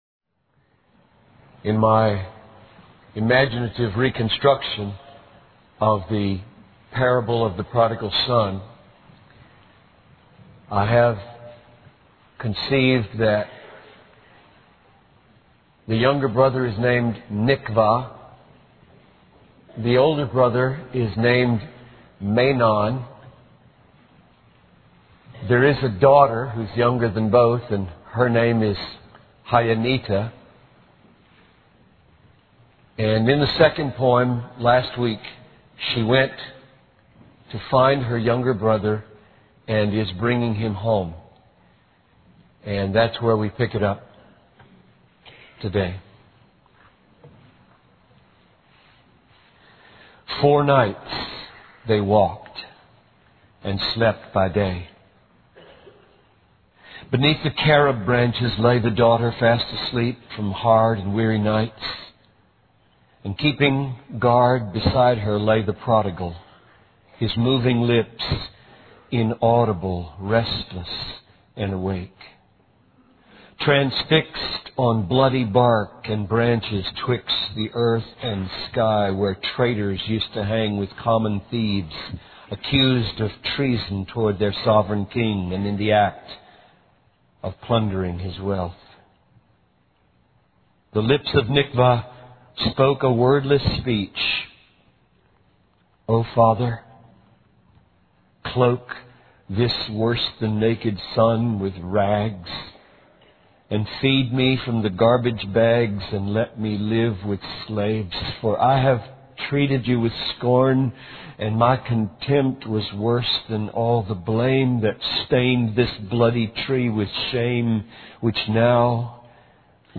The sermon emphasizes the themes of grace, redemption, and the joy of returning home, highlighting that true joy and acceptance come from the father's love, not from our own works or merits.